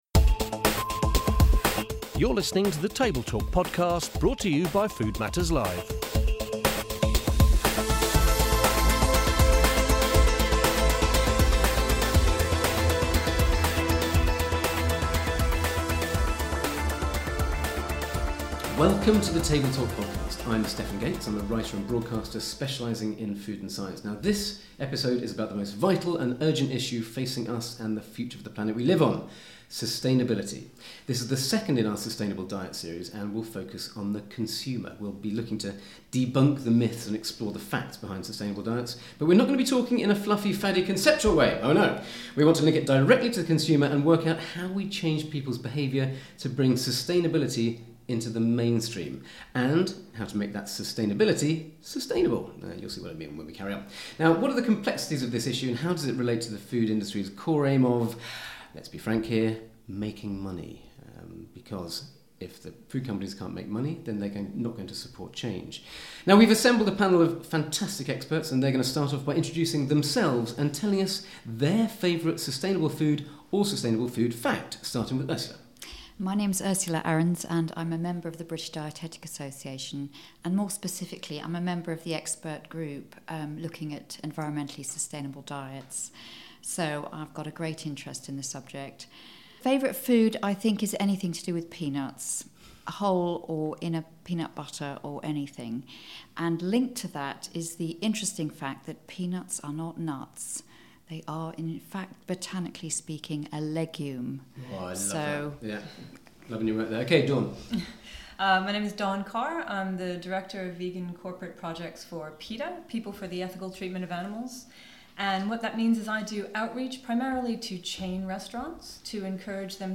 An expert panel